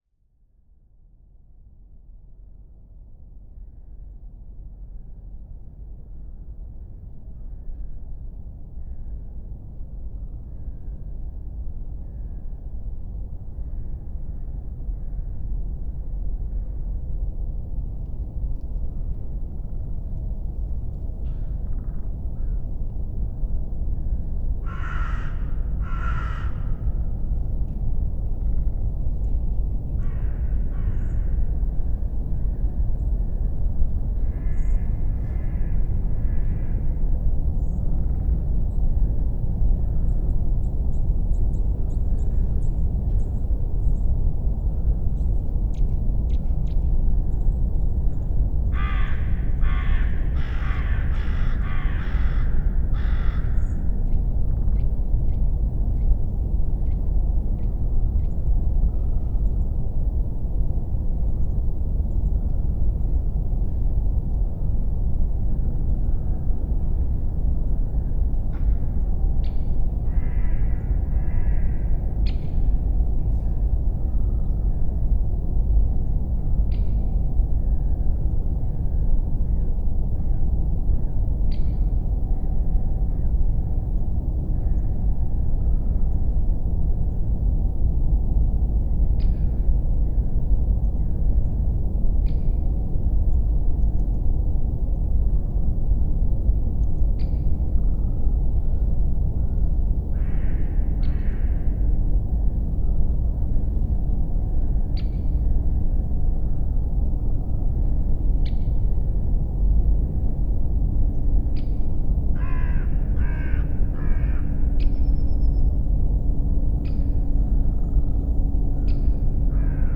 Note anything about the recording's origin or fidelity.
PFR12257, 170119, 01:51pm, ambience recording, -5C, Bienitz near Leipzig, Sennheiser MKH 8020/30